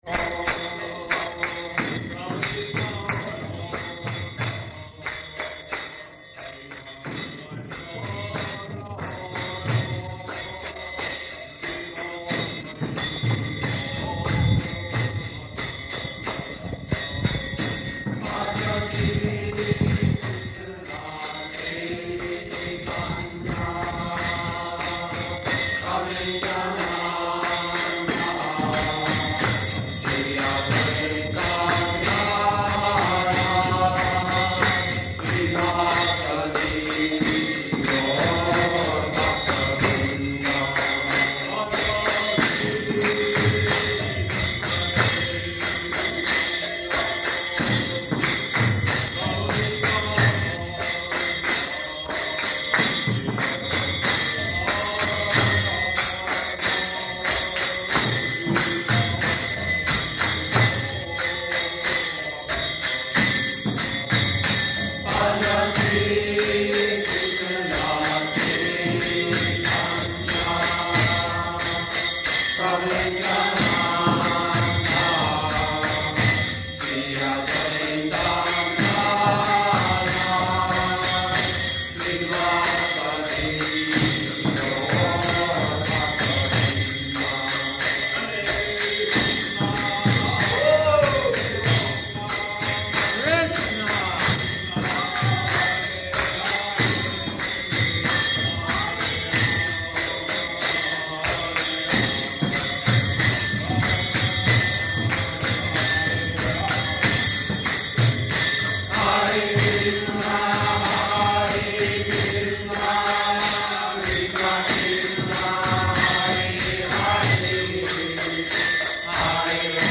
Bhagavad-gītā 4.7–10 --:-- --:-- Type: Bhagavad-gita Dated: January 6th 1969 Location: Los Angeles Audio file: 690106BG-LOS_ANGELES.mp3 [ kīrtana ] Prabhupāda: All glories to the assembled devotees.